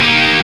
Index of /90_sSampleCDs/Roland L-CDX-01/GTR_GTR FX/GTR_Gtr Hits 1
GTR ROCKC0GL.wav